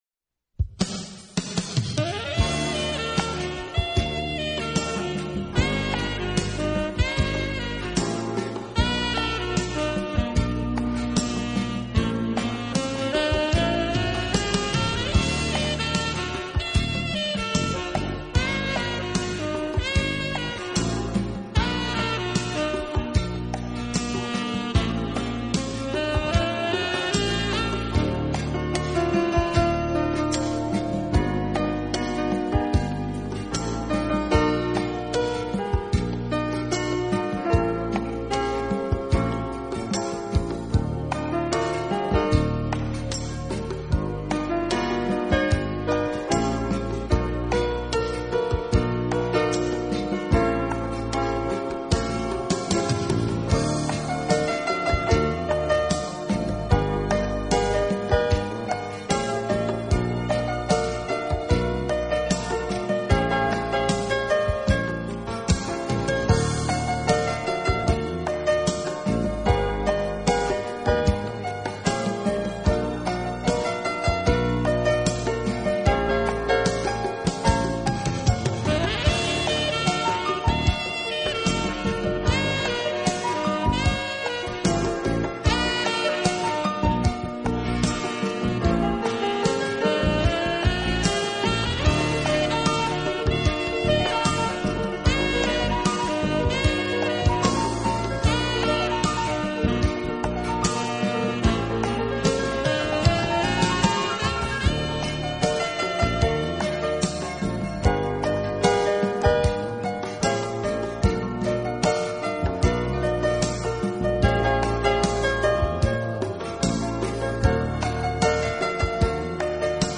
清新的演绎，不再是单调的陈叙。